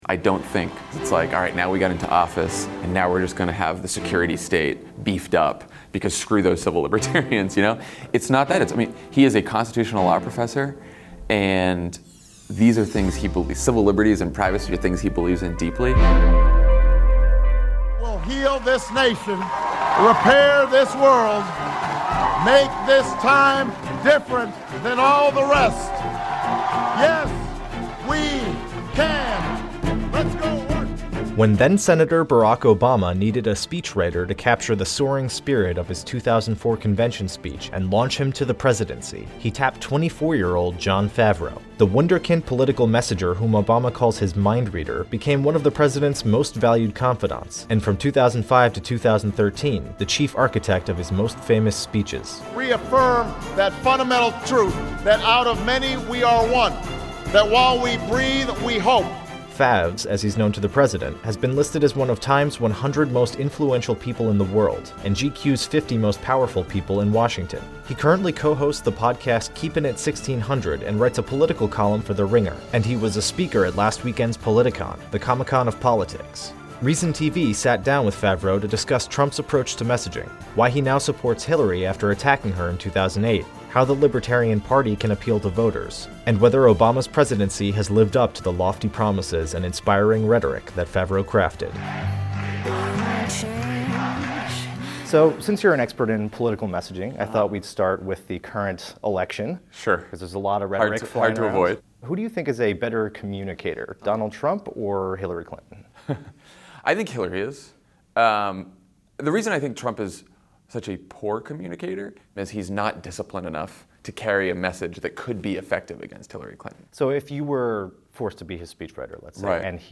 Reason TV sits down with Jon Favreau for a spirited discussion of Trump's rhetoric and Obama's record on mass surveillance, whistleblowers, drugs, & drones.